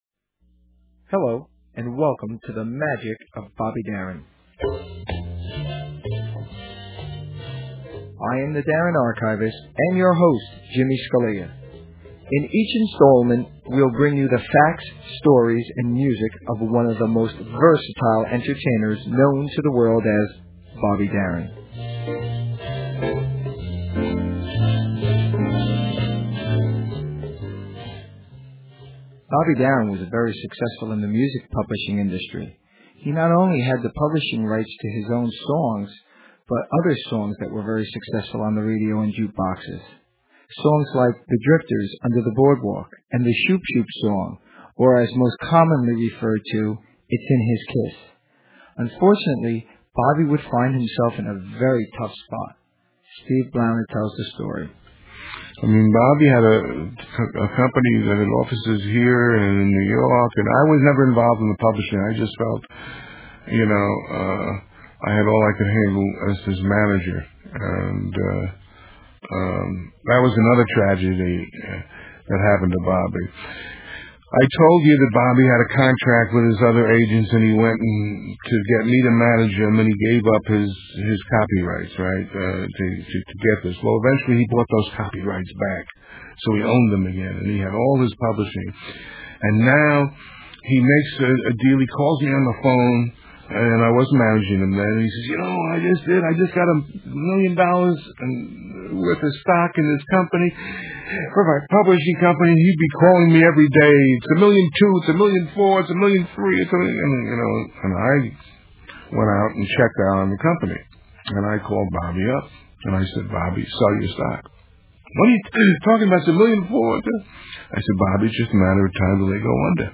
Technical Note: Please keep in mind that due to time and space contraint on the internet, and legal worries of sharing too much, the music portions have been edited and the quality is subpar.